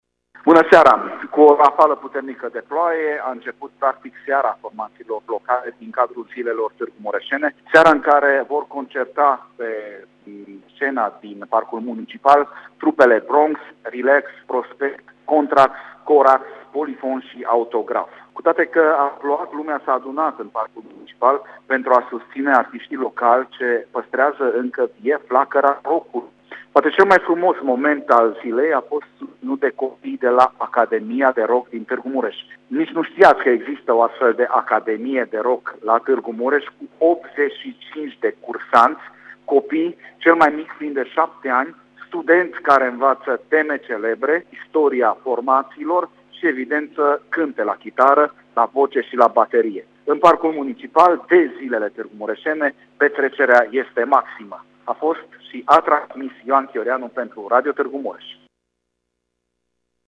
A 21-a ediție a Zilelor Tîrgumureșene prilejuiește, la această oră, un concert de muzică rock susținut de cunoscute formații tîrgumureșene.
Din Parcul Sportiv Municipal transmite